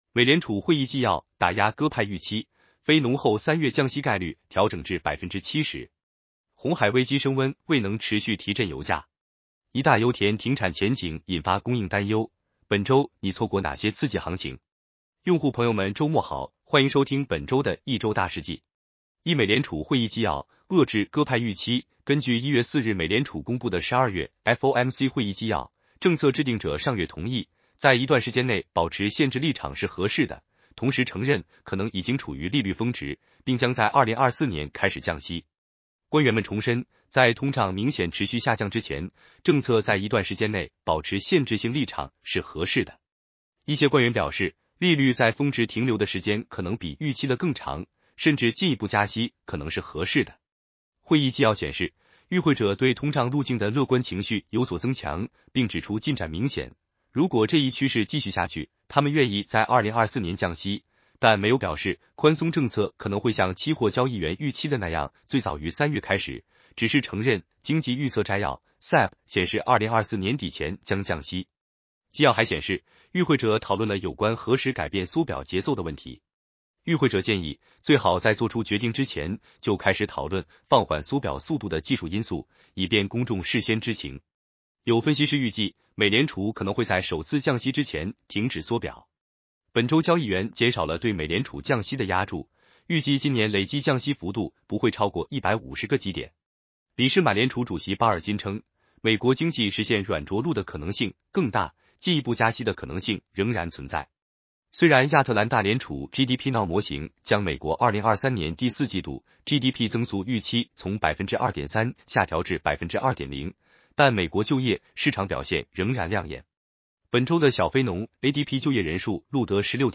男生普通话版 下载mp3 行情回顾 现货黄金2023年累计上涨13% ，创2020年以来的最佳年度表现。